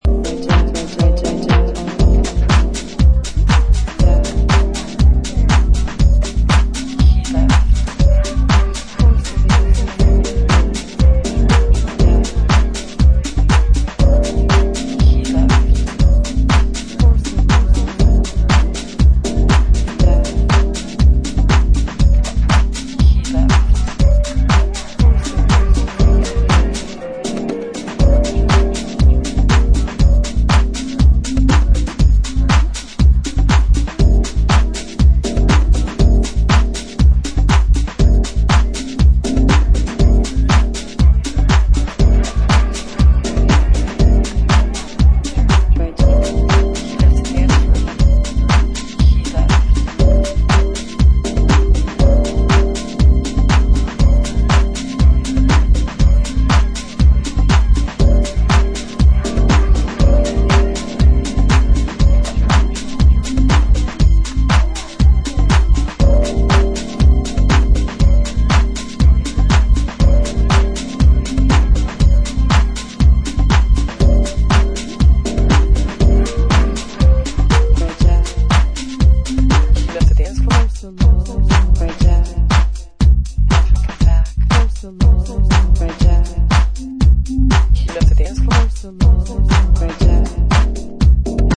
Some decent old fashioned house tracks.